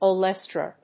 /oˈlɛstrʌ(米国英語), əʊˈlestrʌ(英国英語)/